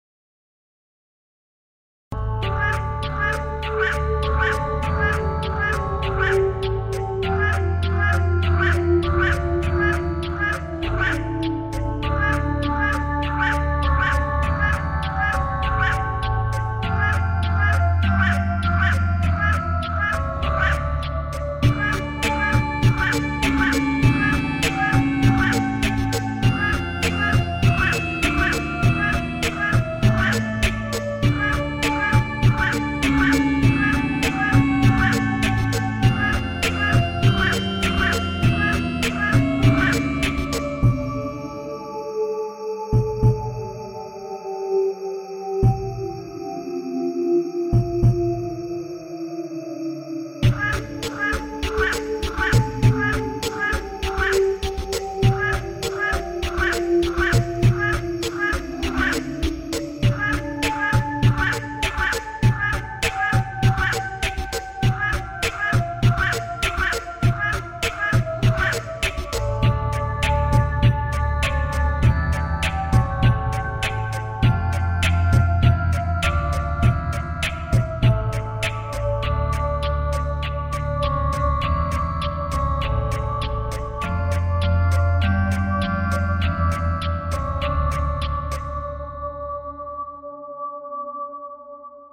Soundiron Snack 是一个由 Soundiron 开发的免费的 Kontakt 音源，它使用了猫、狗、鸟、狮子、老虎、蟋蟀等动物的声音作为原始素材，经过创意的声音设计，生成了一些有趣而独特的音乐效果。
造了一些电子打击乐、持续的垫音、风琴和合成器音色等效果。这些效果都具有独特的音色和氛围，可以用于各种风格的音乐制作。